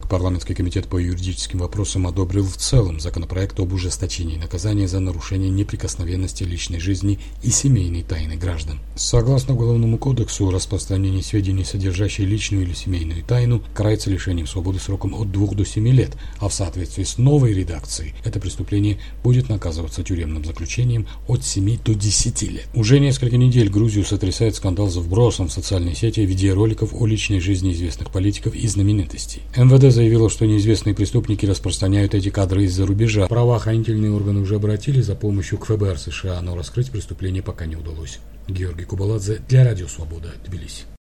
Рассказывает корреспондент